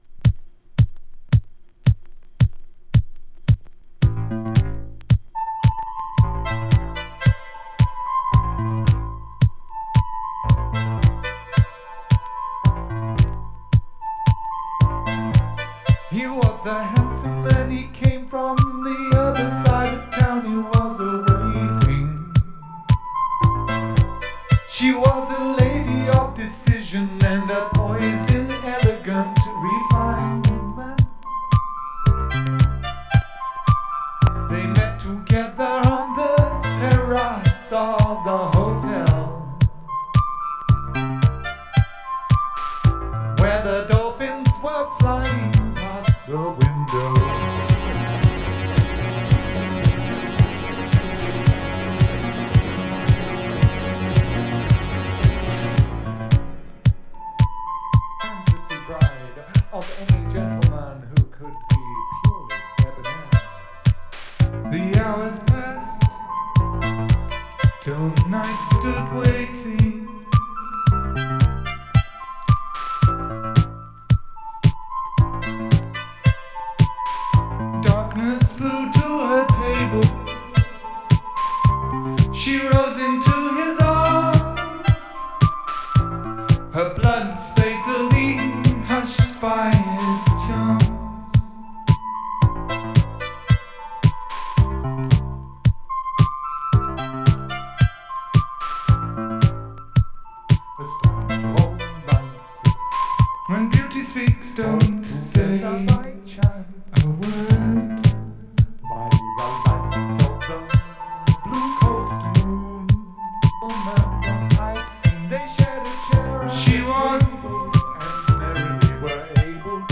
ニューウェイヴ/インダストリアル/エクスペリメンタル寄りのサウンドが特徴です。